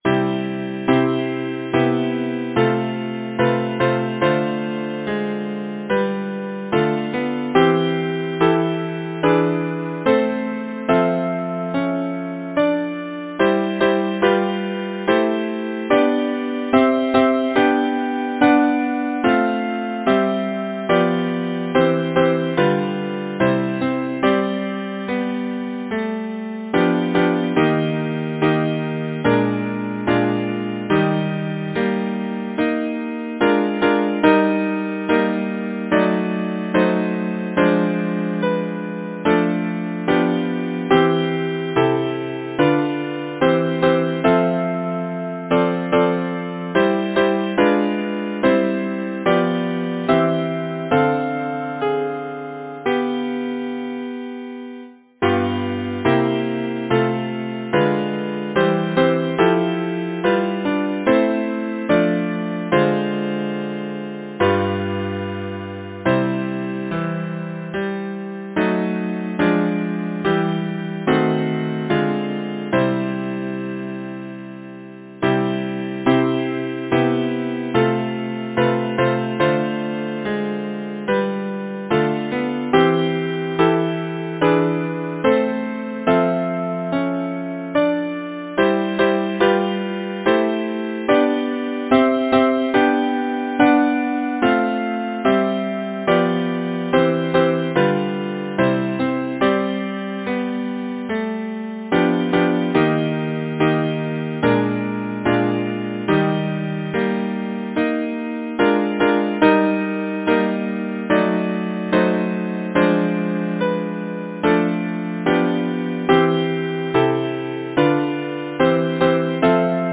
Title: I think on thee Composer: Cuthbert Harris Lyricist: Thomas Kibble Hervey Number of voices: 4vv Voicing: SATB Genre: Secular, Partsong
Language: English Instruments: A cappella